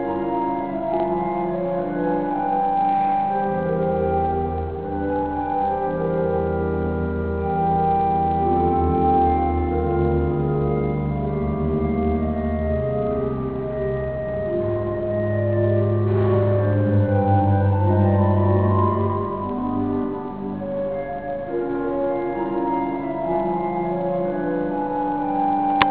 背後にはパイプオルガンが堂々と構えている。
そしてパイプオルガンの響き…(-_-)
演奏会ではなく練習をしているような感じの
弾きかたではあったが、聖堂全体をやわらかく震わせるような
カメラについていた録音機能を初めて使ってみたのだが、